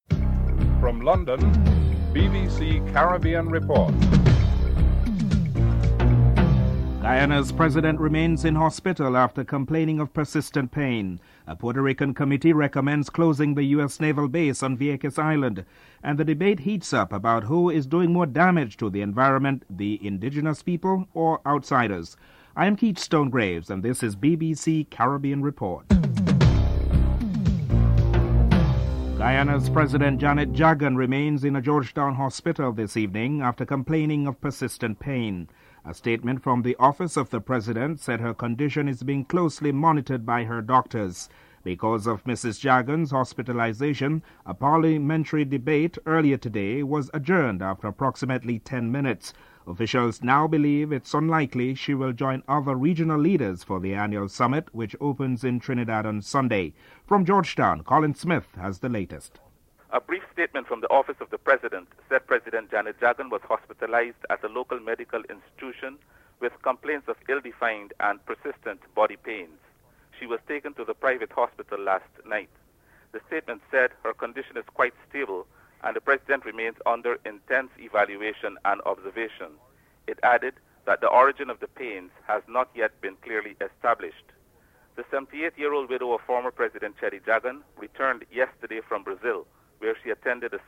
7. Regional experts discuss trends in the regional decline in tourist arrivals in the Caribbean region.